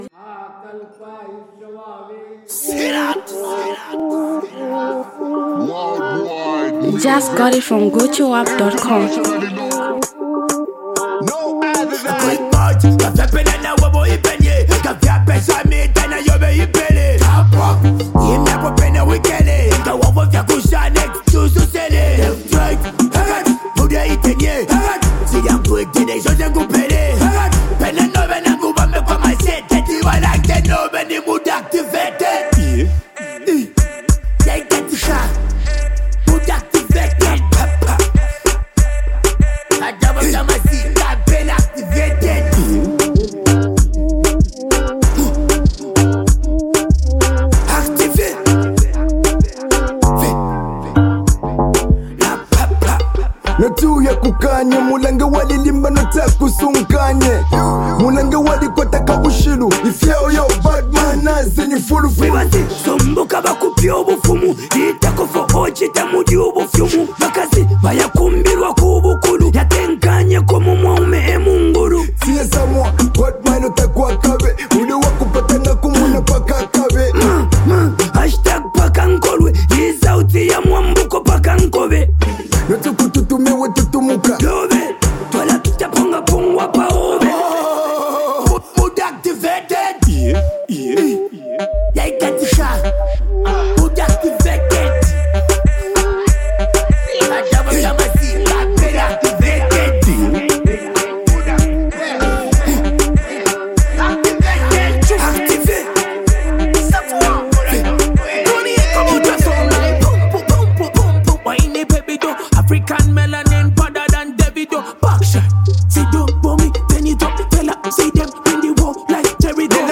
ghetto vibing genre
hardcore rappers